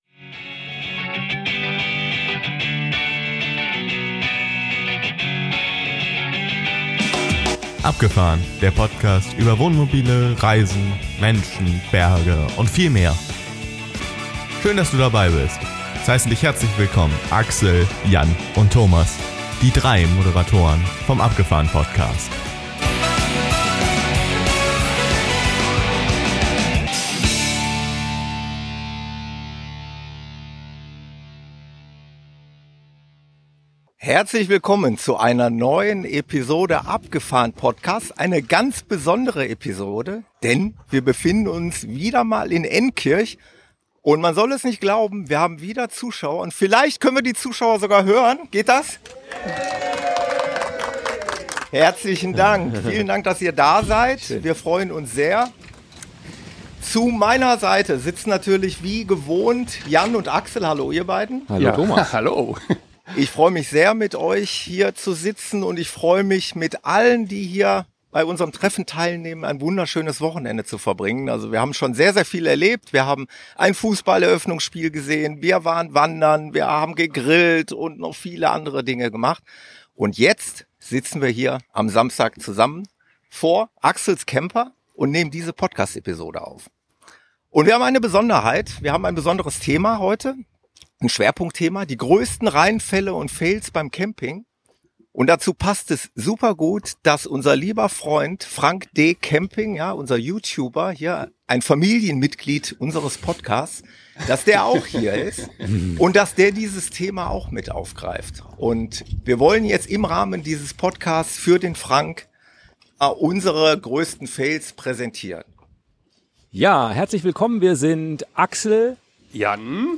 Und wieder haben wir vor Publikum eine Live-Episode aufgezeichnet.
Hört, welche Reinfälle wir und unsere Gäste vom Hörer-Treffen schon so erlebt haben. Darüber hinaus gibt es Interviews direkt vom Moselsteig und aus dem Weingut Immich-Anker. Wir beenden diese Episode stilecht mit Lagerfeuerromantik.